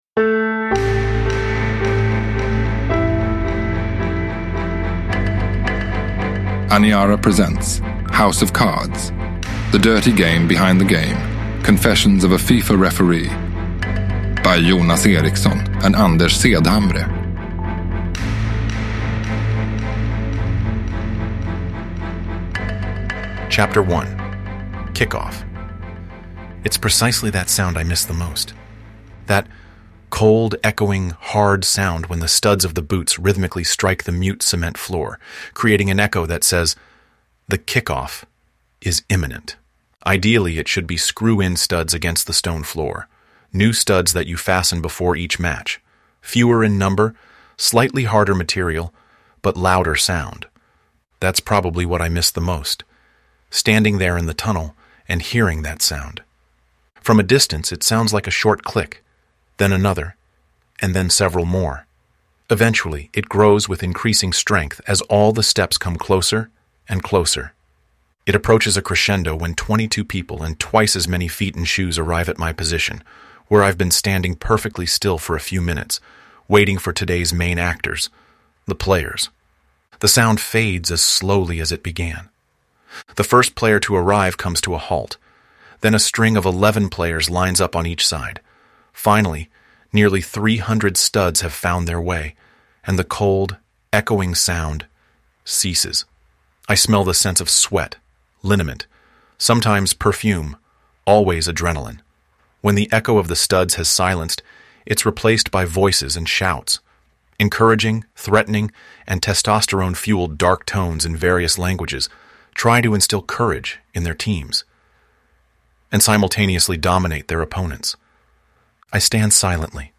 Uppläsare: AI Alfred
Ljudbok